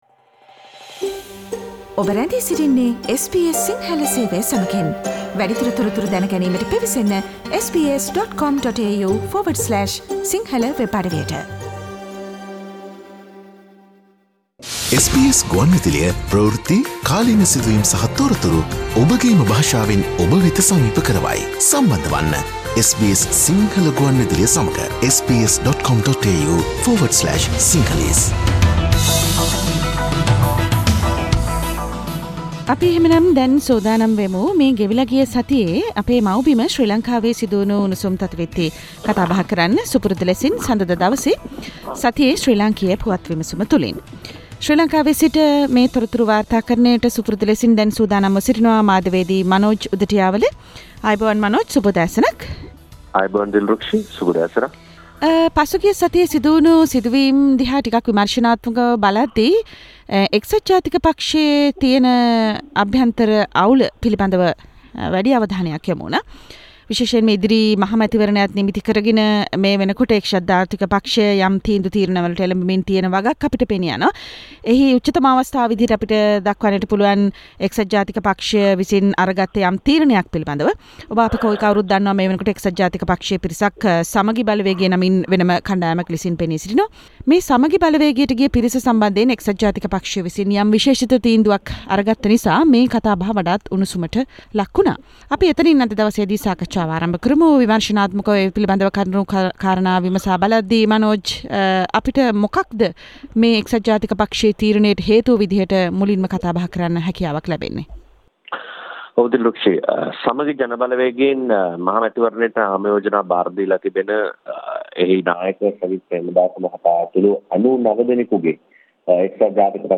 Sri Lankan news wrap Source: SBS Sinhala radio